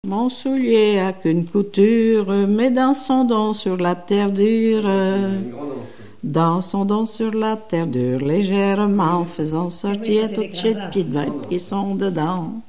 grand'danse
Genre énumérative
Pièce musicale inédite